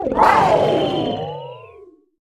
Cri de Miraidon Mode Ultime dans Pokémon Écarlate et Violet.
Cri_1008_Ultime_EV.ogg